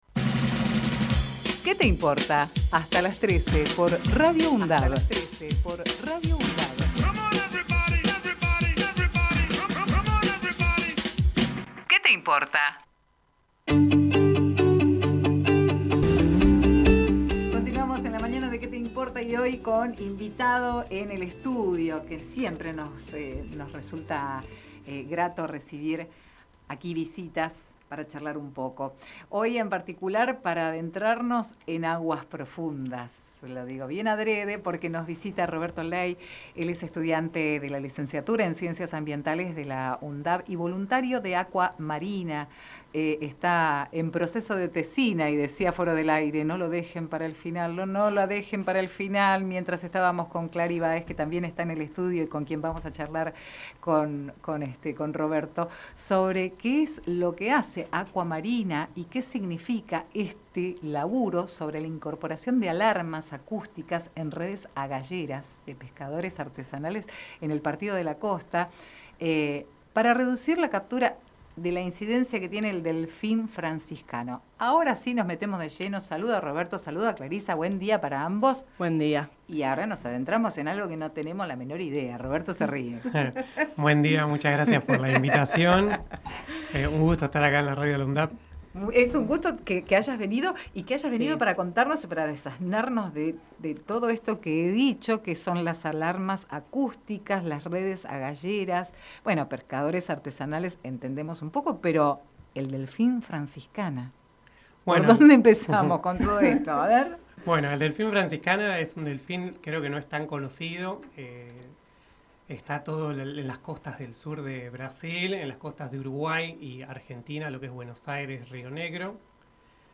Compartimos con ustedes la entrevista realizada en "Qué te importa?!"